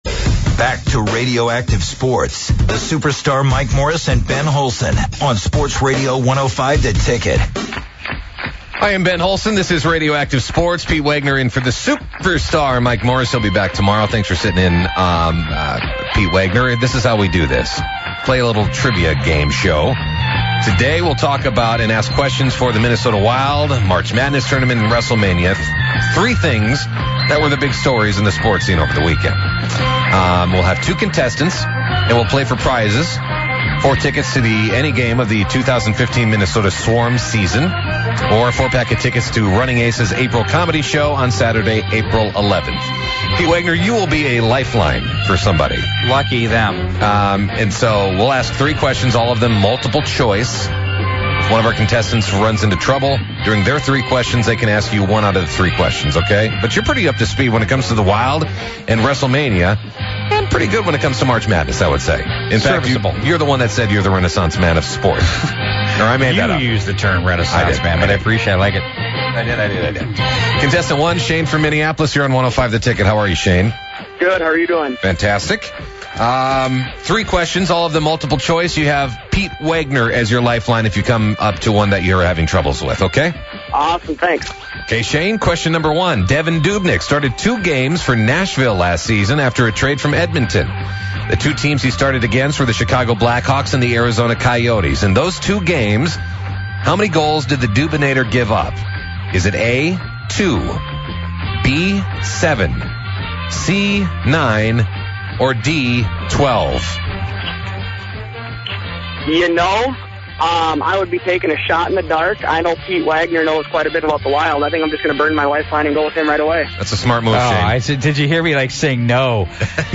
joined by some callers